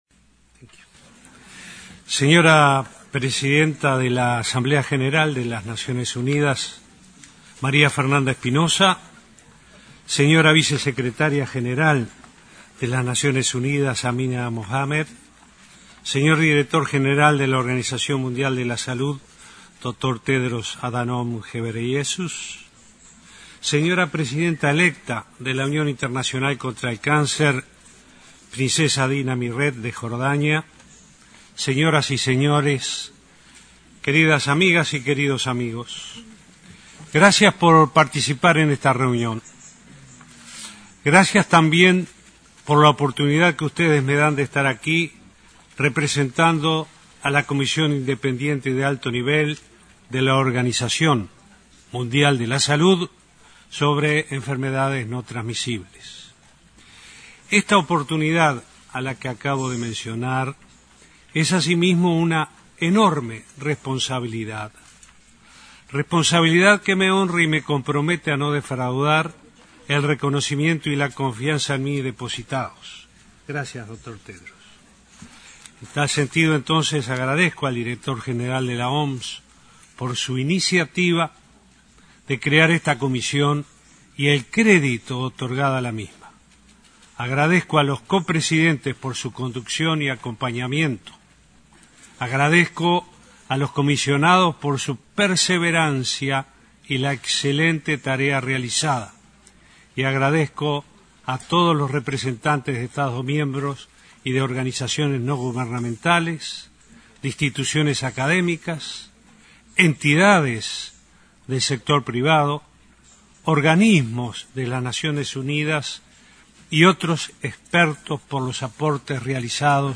“Es hora de actuar y esto no deja lugar a dudas”, sostuvo el presidente Vázquez durante su disertación en la Reunión de Alto Nivel sobre Enfermedades No Transmisibles de la OMS, que se realizó este jueves en el marco de la Asamblea General de Naciones Unidas. Sostuvo que es necesario involucrar a la sociedad civil y al sector privado en la lucha contra esta pandemia y excluir a la industria tabacalera.